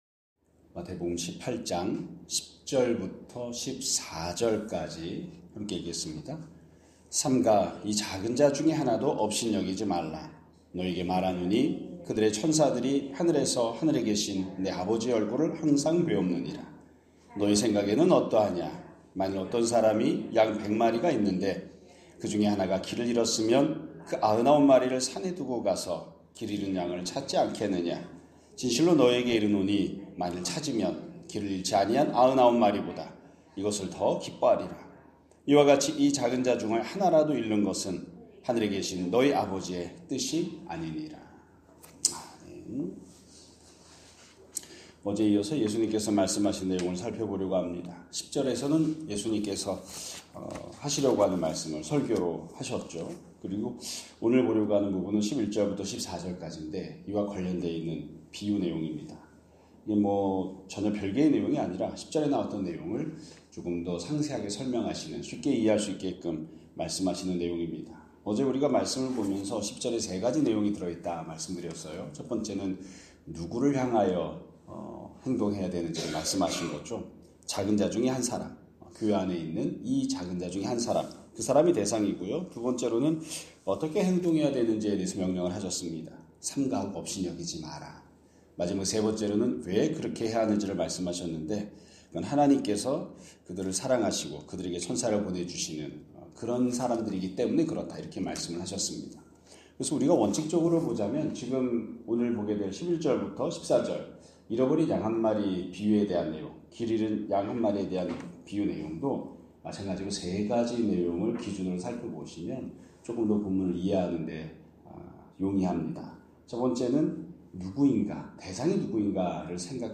2025년 12월 9일 (화요일) <아침예배> 설교입니다.